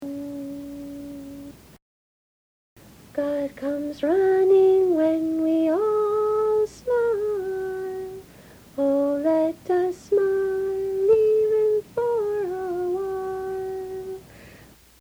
See individual song practice recordings below each score.
Many, especially the ones below the individual scores, were recorded on an old, portable cassette tape and have some distortion.